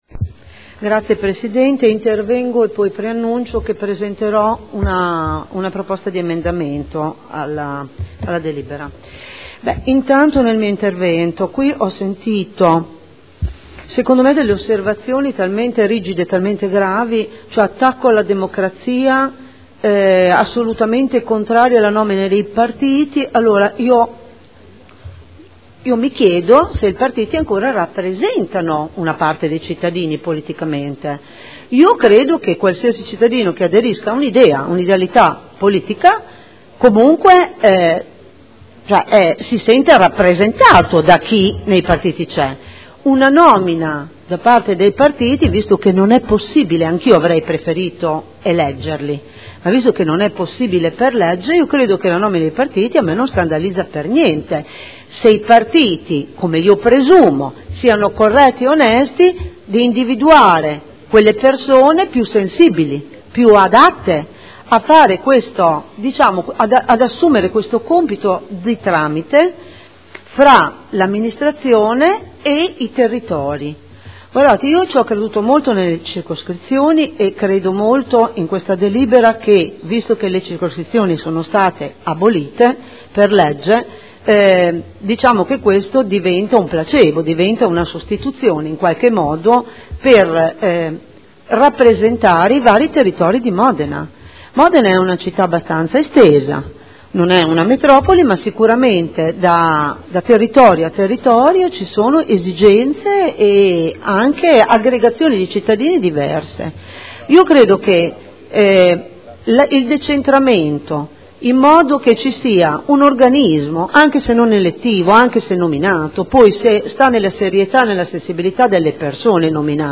Seduta del 3 aprile. Proposta di deliberazione: Regolamento di prima attuazione della partecipazione territoriale – Approvazione. Dibattito